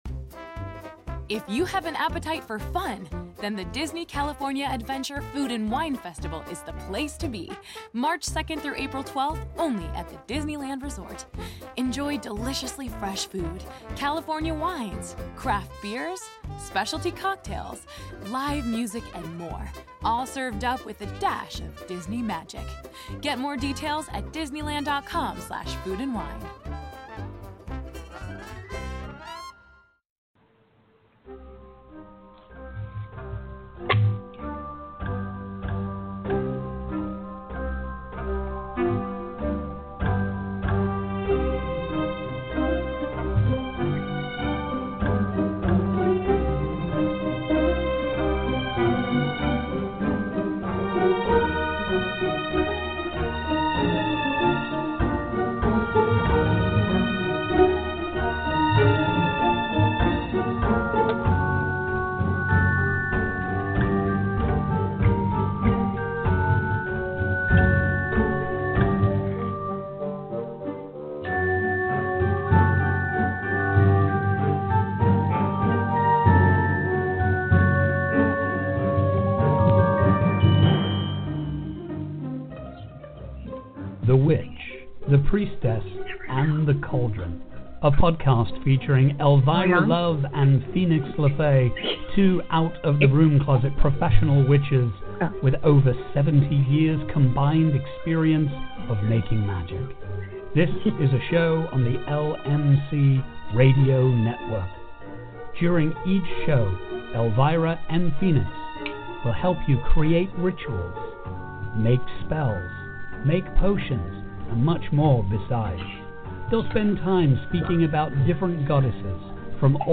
This episode is a live snapshot in time of people gathering to learn, the chatter of strangers who become friends, and the magic of shared space.